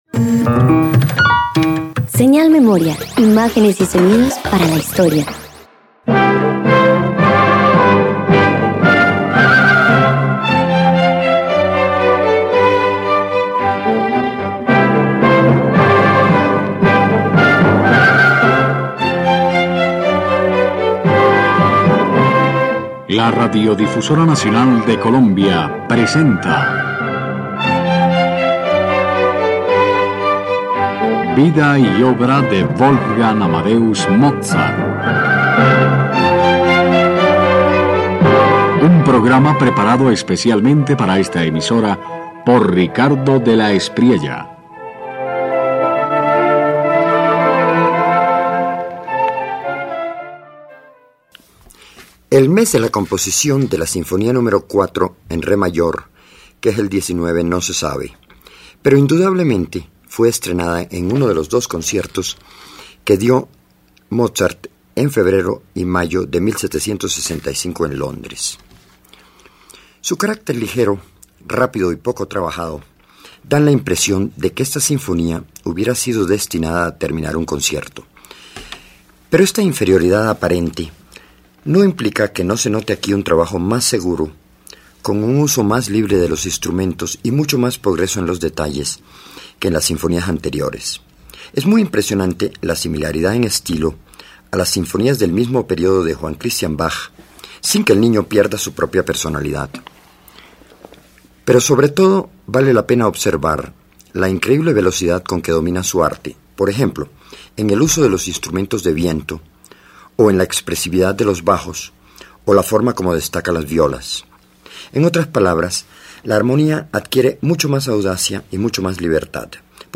La sinfonía No. 4 sorprende por la energía de un niño capaz de pensar como un adulto. Junto a ella, la sonata en do para clavecín revela un universo íntimo de elegancia y virtuosismo, mientras que las primeras arias dan voz a la emoción pura.
009 Sinfonía No 4 Sonata en Do mayor para Clavecín. Primeras Arias de Mozart 2.mp3